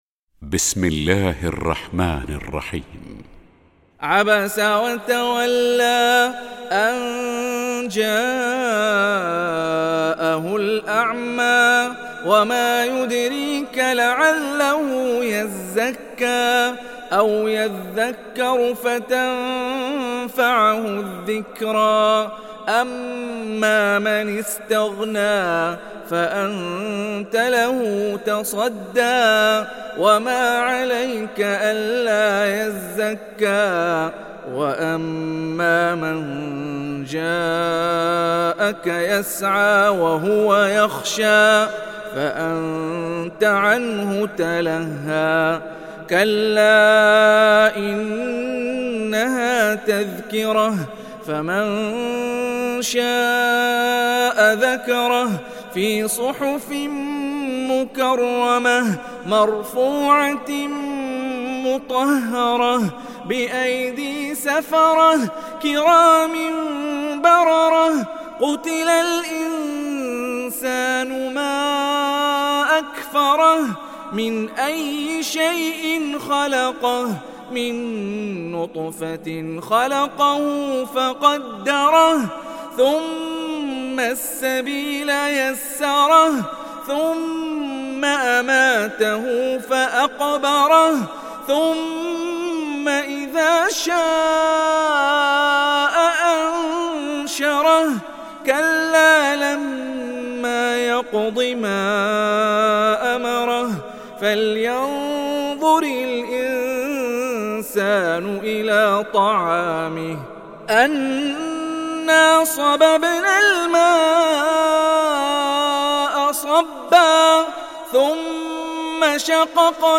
دانلود سوره عبس mp3 هاني الرفاعي (روایت حفص)